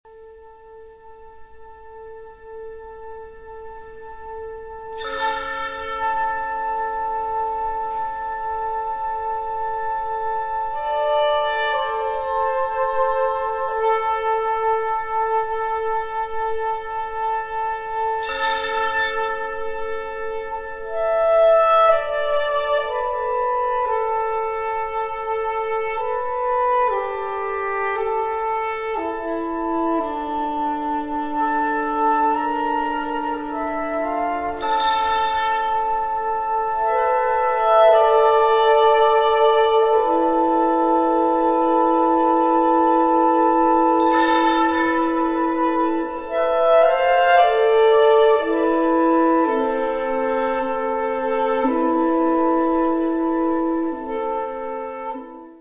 Category Concert/wind/brass band
Subcategory Original contemporary music (20th, 21st century)
Instrumentation Ha (concert/wind band)